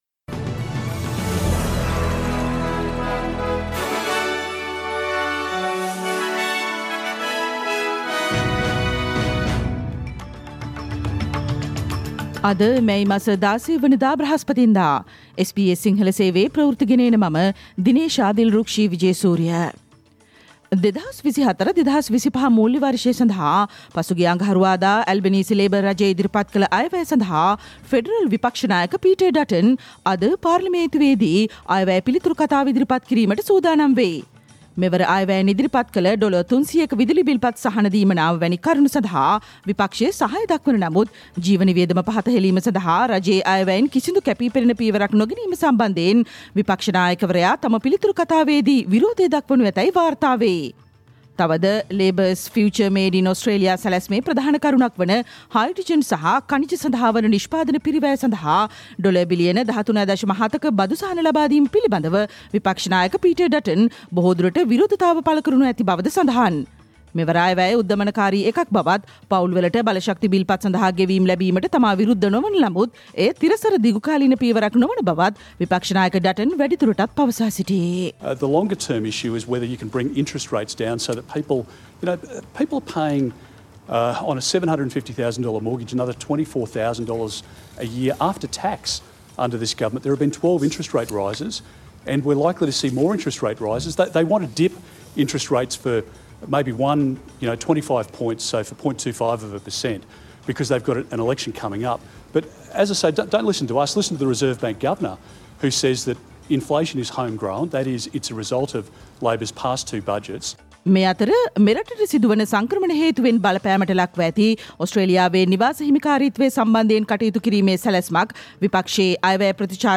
Australia news in Sinhala, foreign and sports news in brief - listen, today – Thursday 16 May 2024 SBS Radio News